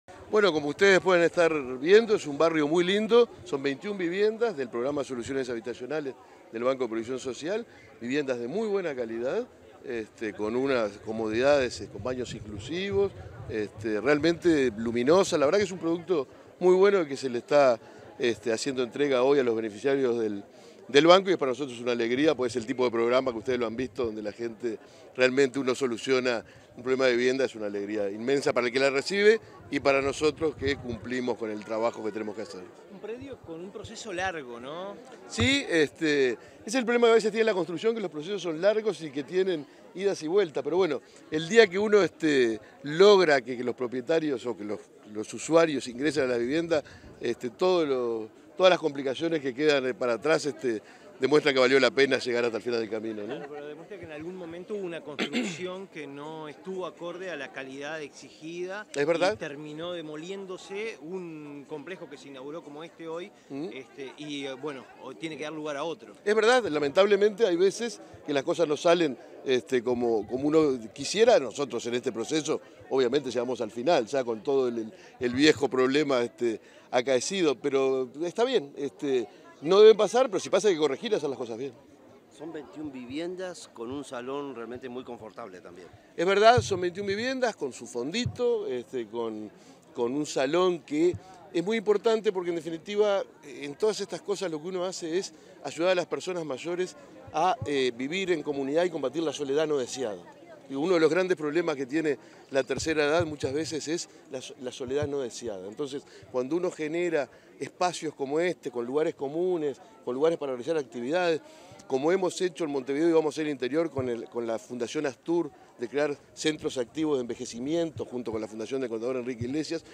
Declaraciones a la prensa del presidente del BPS, Alfredo Cabrera
Declaraciones a la prensa del presidente del BPS, Alfredo Cabrera 08/06/2023 Compartir Facebook X Copiar enlace WhatsApp LinkedIn Luego de la entrega de viviendas a jubilados y pensionistas en Florida, este 8 de junio, el presidente del Banco de Previsión Social (BPS), Alfredo Cabrera, realizó declaraciones a la prensa.